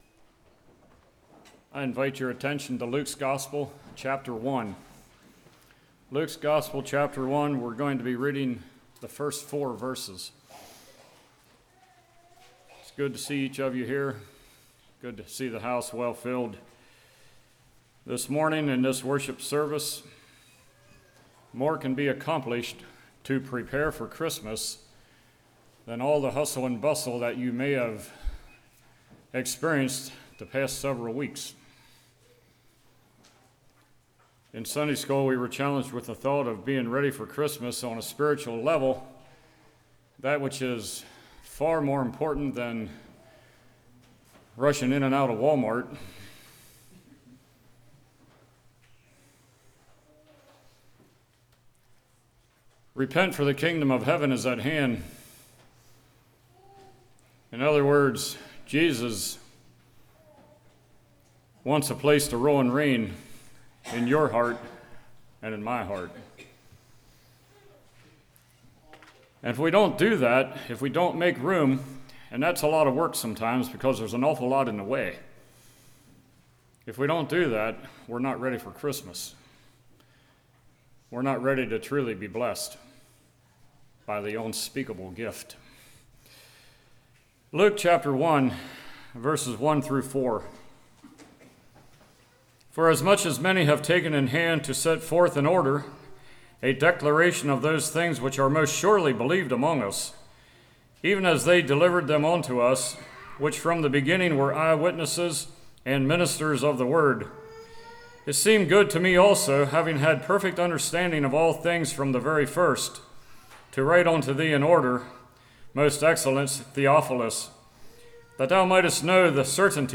Luke 1:1-4 Service Type: Morning John the Baptist Baptism of Jesus Caesar’s Taxing Born & Crucified in March « Fire of God Repentance »